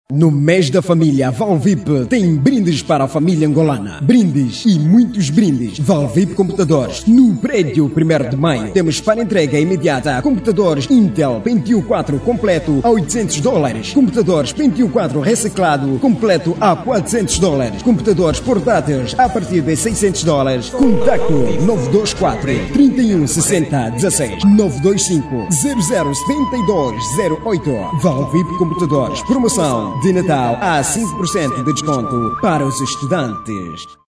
Masculino
Voz Varejo 00:34